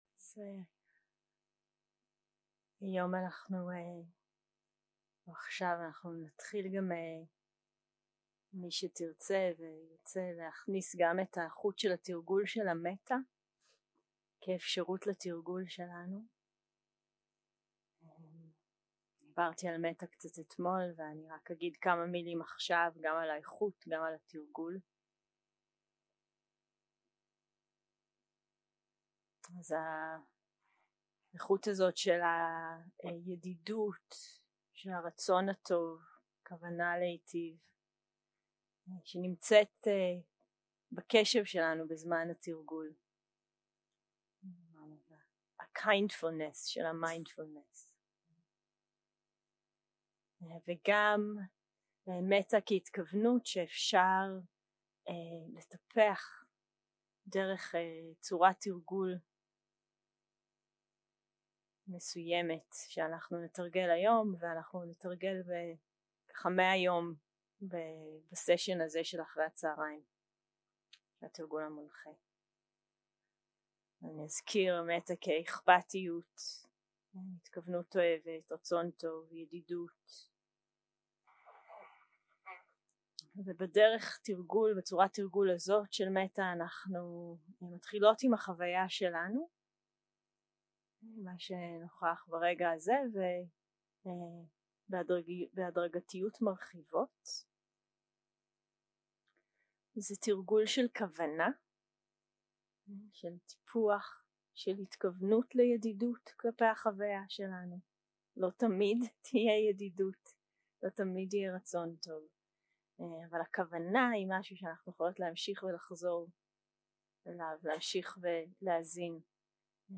יום 3 - הקלטה 6 - צהרים - מדיטציה מונחית - מבוא להנחיות לתרגול מטא
יום 3 - הקלטה 6 - צהרים - מדיטציה מונחית - מבוא להנחיות לתרגול מטא 0:00 0:00 סוג ההקלטה: Dharma type: Guided meditation שפת ההקלטה: Dharma talk language: Hebrew